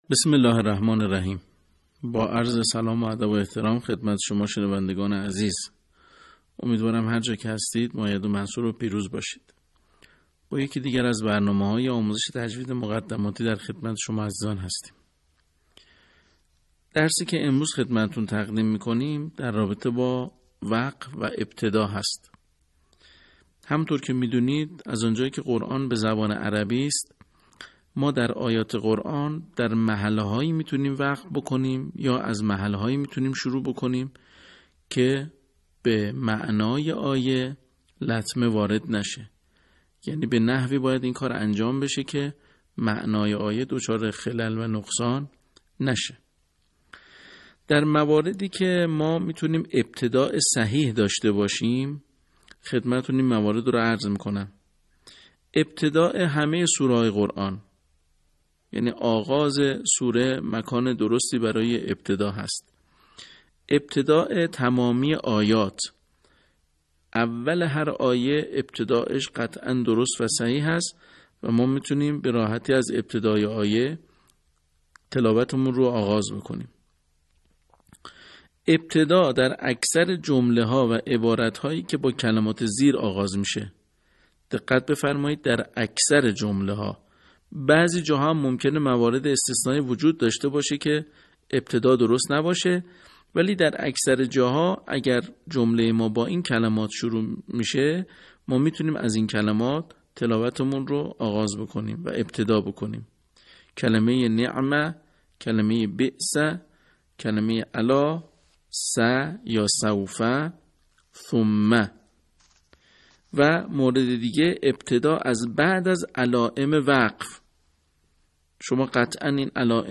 به همین منظور مجموعه آموزشی شنیداری (صوتی) قرآنی را گردآوری و برای علاقه‌مندان بازنشر می‌کند.